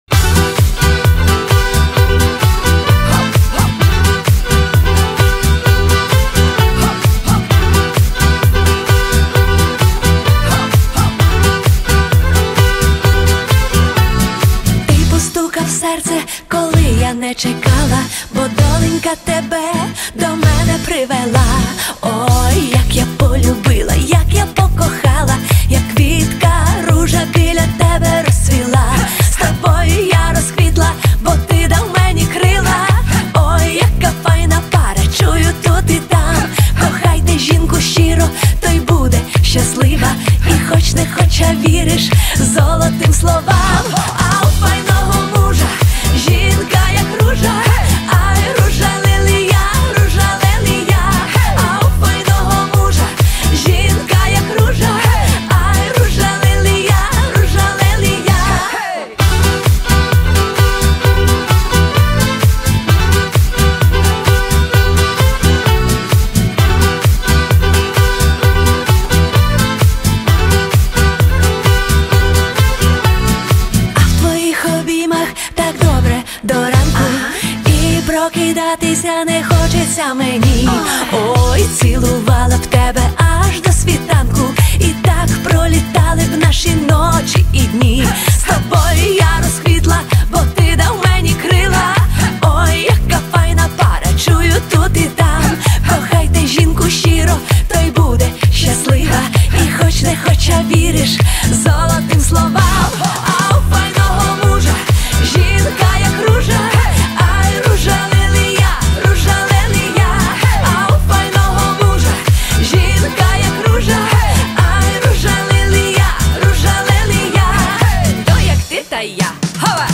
Запальна прем'єра